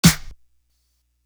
Movie Clap.wav